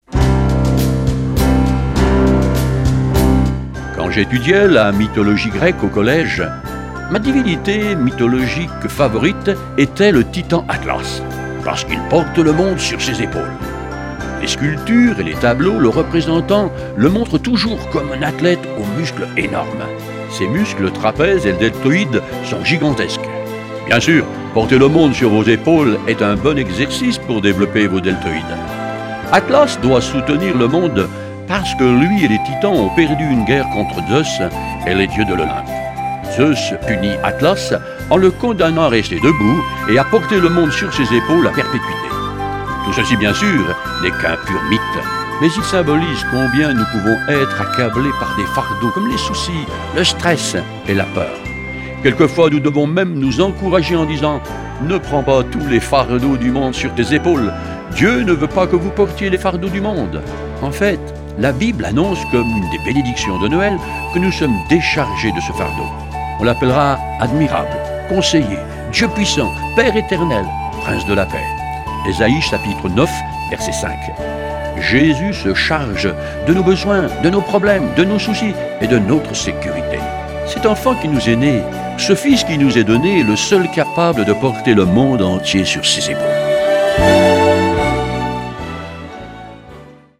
Une série de méditations pour le mois de Décembre
Version audio Phare FM :